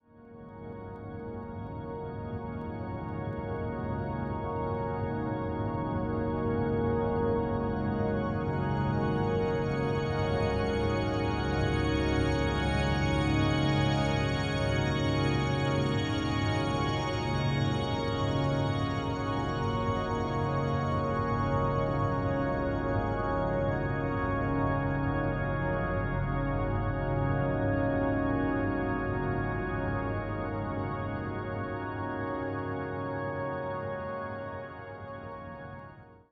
Perfect for ambiance, ambience, ambient.
ambiance ambience ambient background bell boss business computer sound effect free sound royalty free Nature